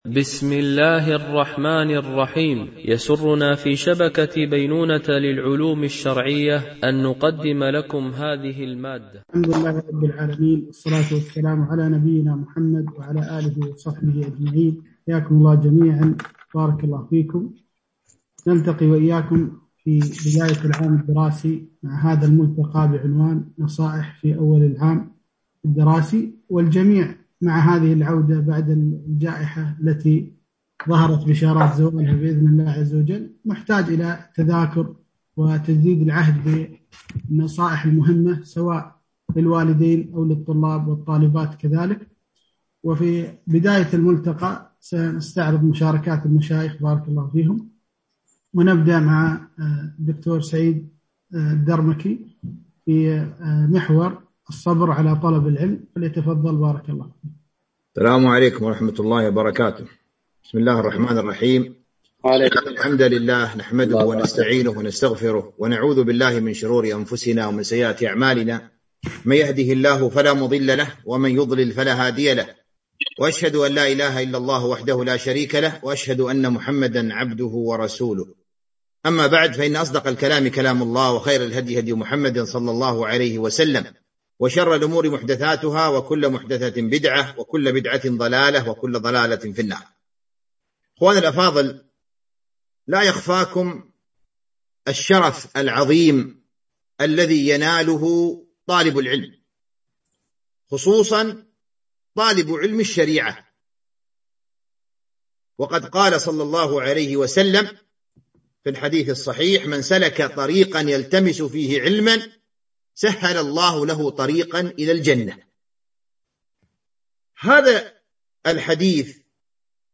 ملتقى حواري بعنوان: نصائح في أول العام الدراسي
الشيخ: لمجموعة من المشايخ الفضلاء